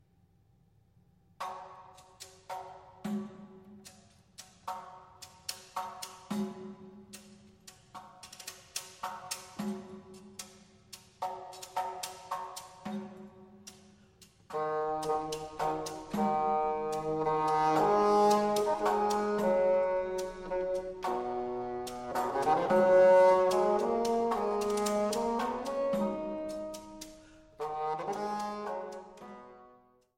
Posaune